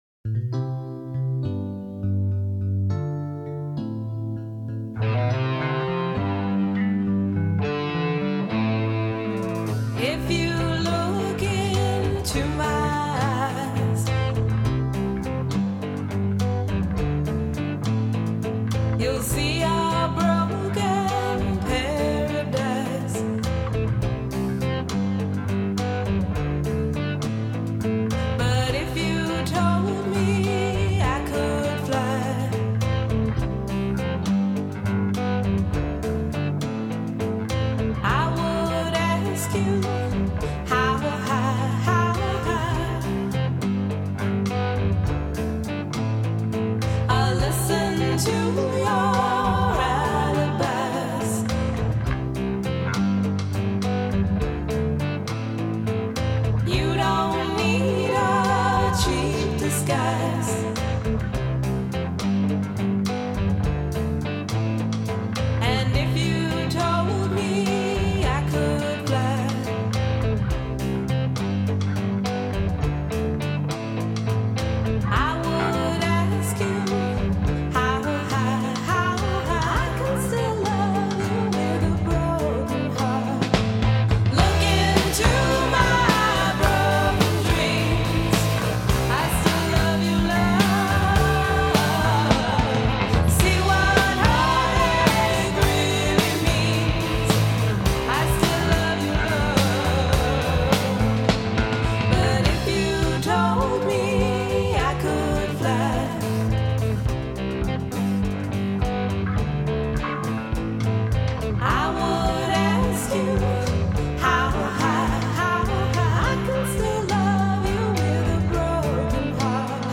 guitar
percussion
bass
vocals and keyboard